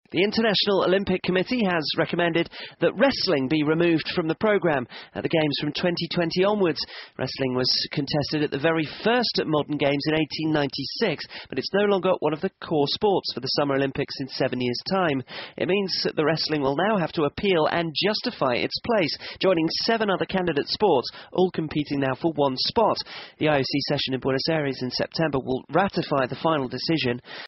【英音模仿秀】摔跤被摔出2020年奥运会 听力文件下载—在线英语听力室